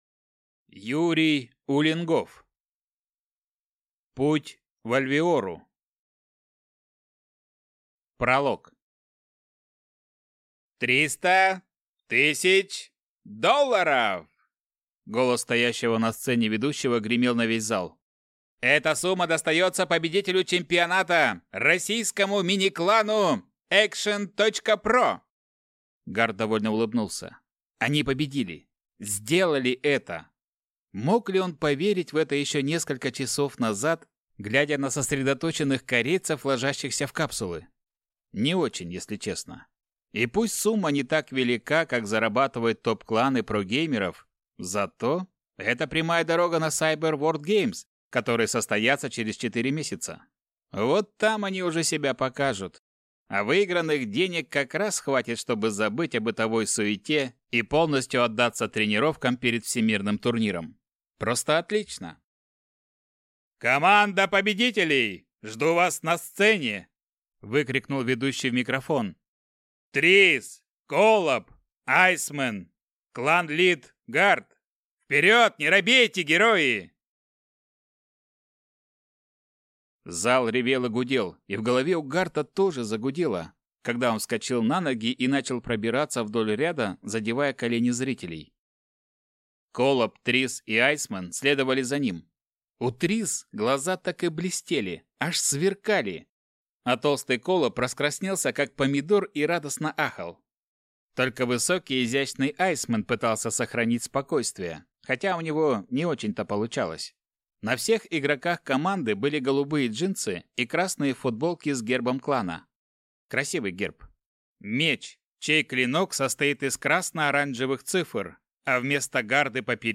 Аудиокнига Путь в Альвиору | Библиотека аудиокниг